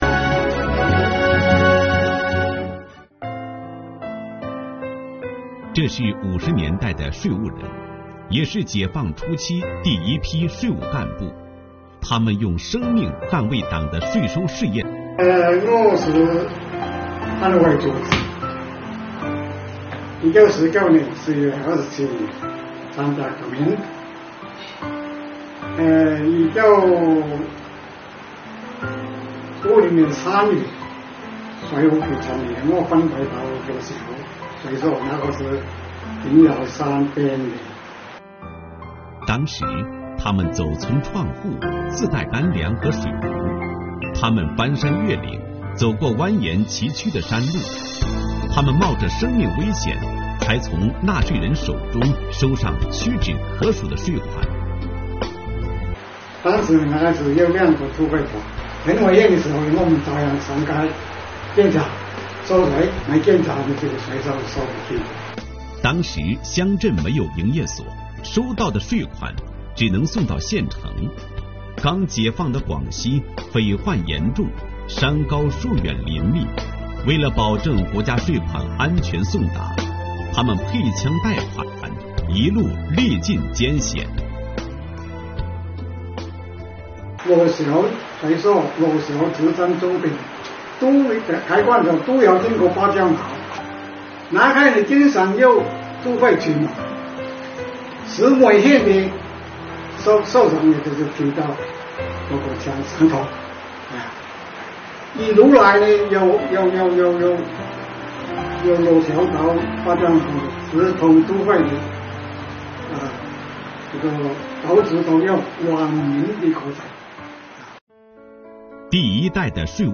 为庆祝建党100周年，深入学习贯彻习近平总书记在党史学习教育动员大会上的重要讲话精神，国家税务总局象州县税务局通过拍摄《初心映照党旗红 砥砺前行“税务蓝”》主题视频，邀请不同年代税务干部讲述入党初心和税收故事的方式，向建党百年华诞献礼。视频再现了老中青三代税务人在不同历史时期、在各自工作岗位，坚定理想信念、积极投身来宾税收事业发展的情景，在全市税务系统党员干部之中引起广泛共鸣，进一步激发起昂首开新局、奋进新征程的磅礴力量。